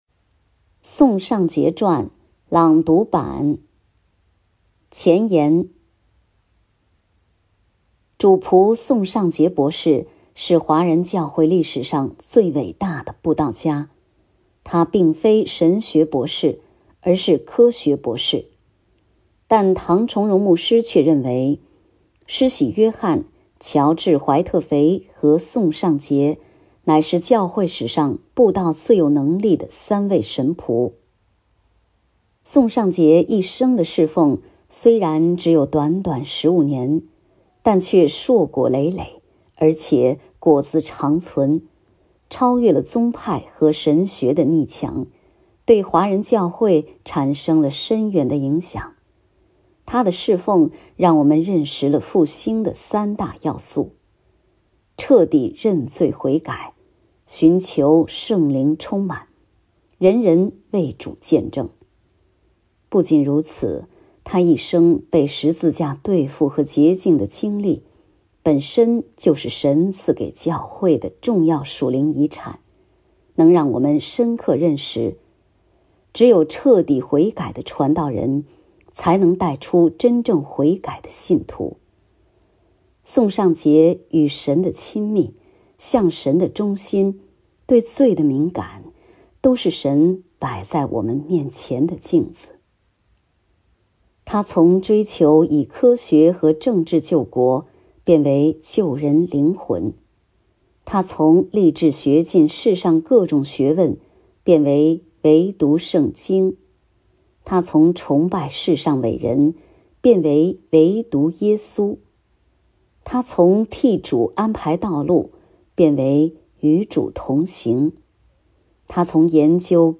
《宋尚节传》朗读版 – 基督、使命与教会
因为有许多读者阅读不便，我们的同工就特地朗读出来。
结束曲：奋兴短歌121首《十字架十字架》